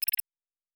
pgs/Assets/Audio/Sci-Fi Sounds/Interface/Error 14.wav at 7452e70b8c5ad2f7daae623e1a952eb18c9caab4
Error 14.wav